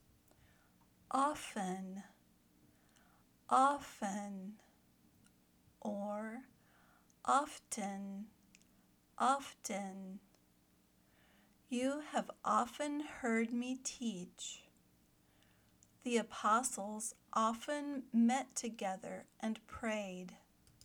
/ˈɔːf n/ or /ˈɔːf tən/   (adverb)